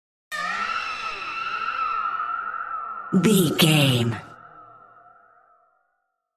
Witches Voice Mid
Sound Effects
Atonal
ominous
eerie
spooky